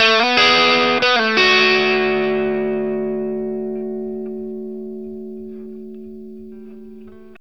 BLUESY1 B 60.wav